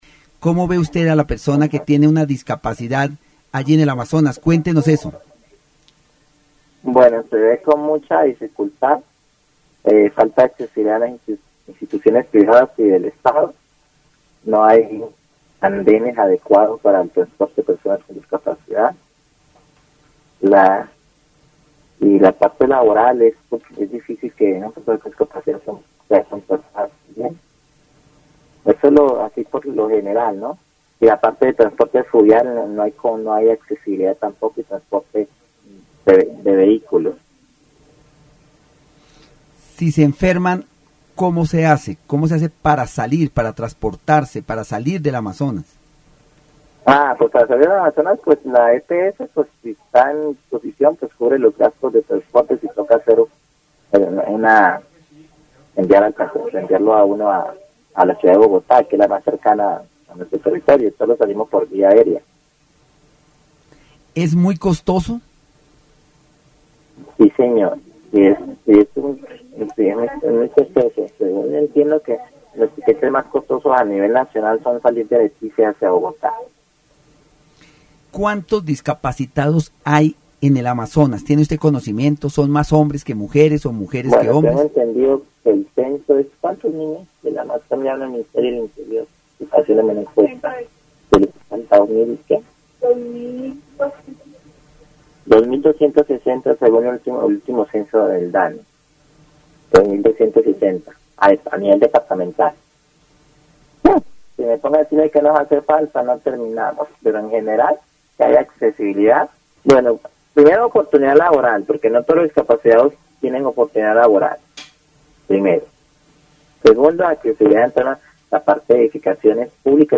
El programa radial aborda la problemática de la accesibilidad para las personas con discapacidad en zonas apartadas, con énfasis en la falta de infraestructura adecuada, las dificultades de transporte y la exclusión laboral. Se destaca la carencia de andenes accesibles, la complejidad para acceder a instituciones privadas y estatales, y la insuficiencia de políticas públicas efectivas.